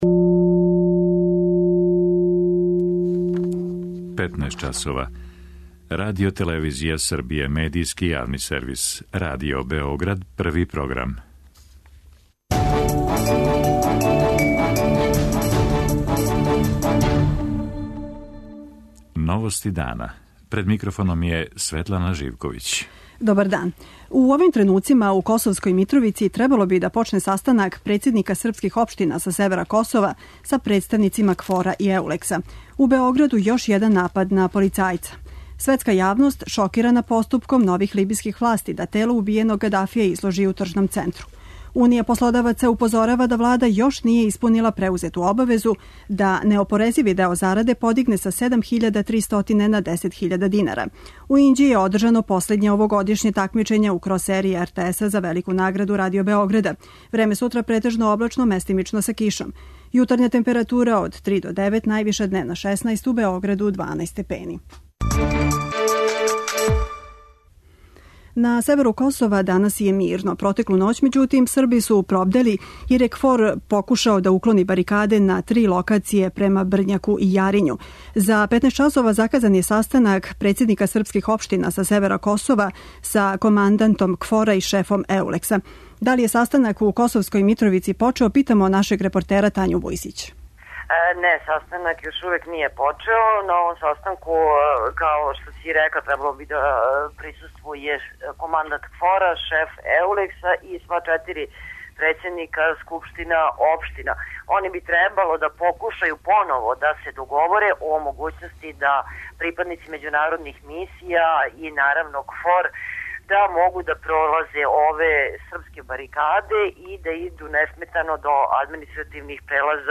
Чућемо репортера РТС из Косовске Митровице.